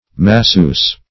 masseuse - definition of masseuse - synonyms, pronunciation, spelling from Free Dictionary
Search Result for " masseuse" : Wordnet 3.0 NOUN (1) 1. a female massager ; The Collaborative International Dictionary of English v.0.48: Masseuse \Mas`seuse"\ (m[.a]*s[^u]z"), n.; pl.